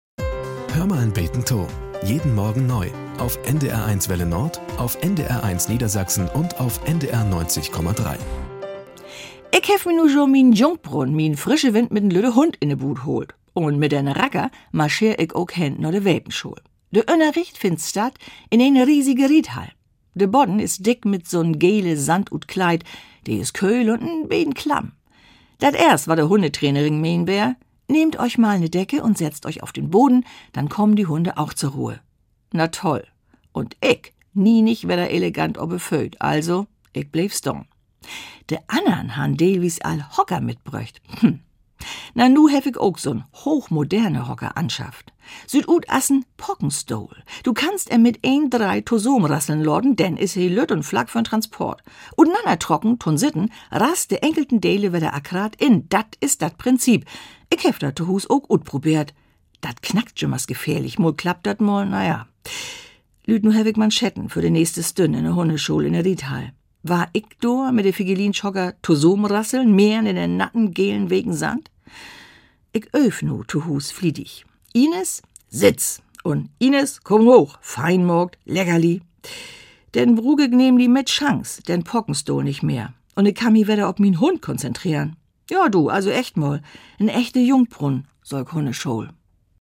Nachrichten - 28.07.2023